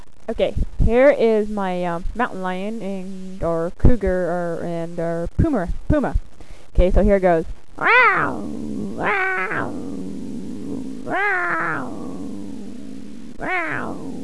Here are a list of Accents and Impersonations I can do...
Cougar